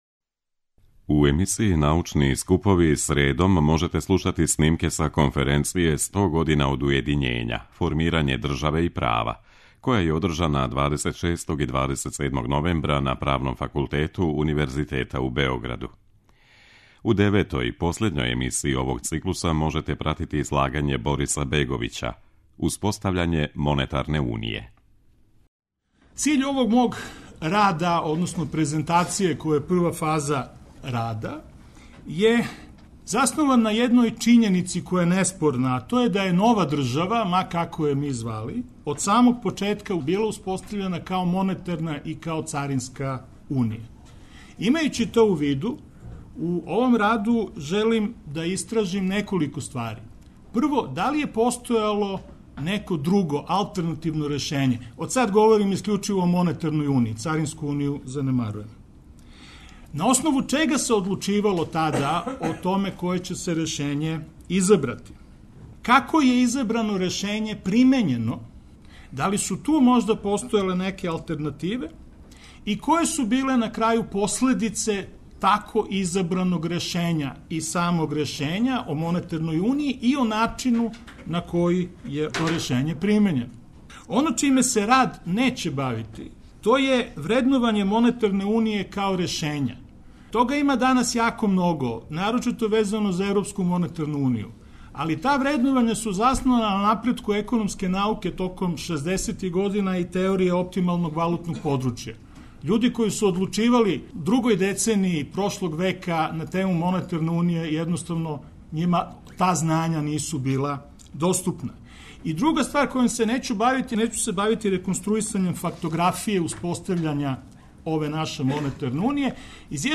преузми : 15.65 MB Трибине и Научни скупови Autor: Редакција Преносимо излагања са научних конференција и трибина.